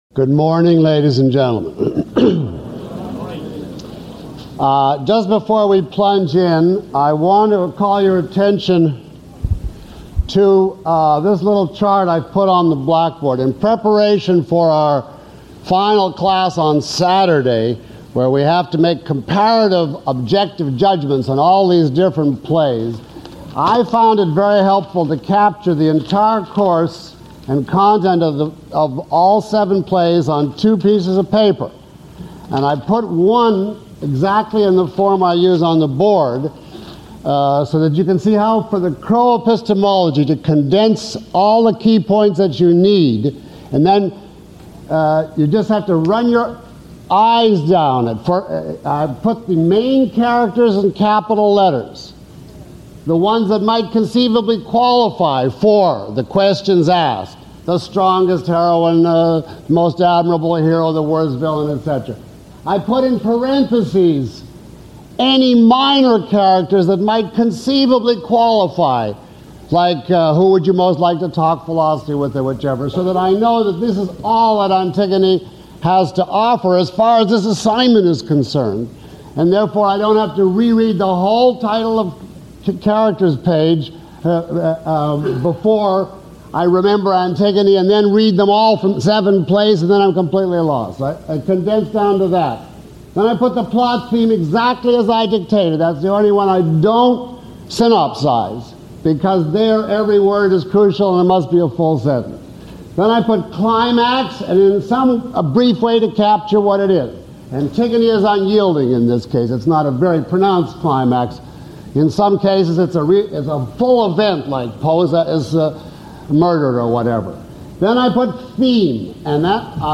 Lecture (MP3) Full Course (ZIP) Lecture Four Course Home Lecture Six Questions about this audio?
Q&A Guide Below is a list of questions from the audience taken from this lecture, along with (approximate) time stamps. 1:38:25 You said that Ibsen’s twelve prose plays made up a cycle.